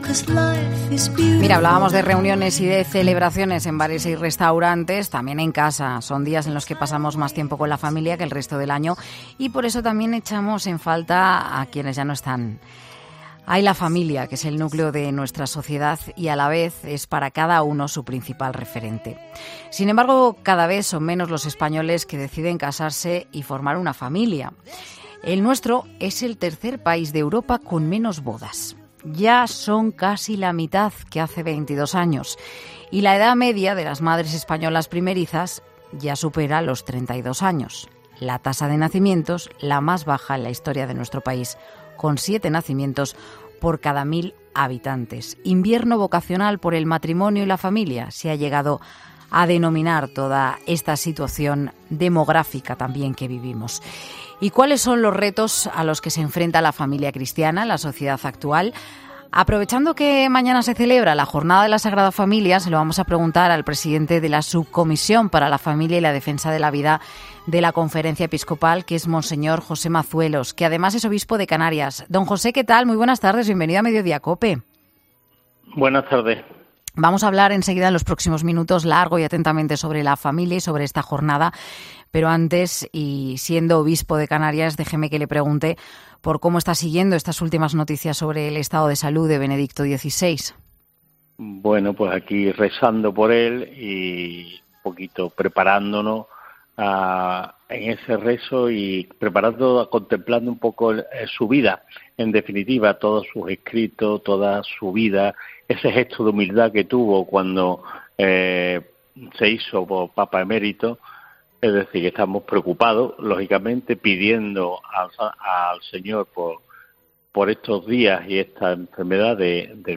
El obispo de Canarias y presidente de la Subcomisión para la Familia y la Defensa de la Vida de la Conferencia Episcopal ha defendido en 'Mediodía COPE' el modelo de familia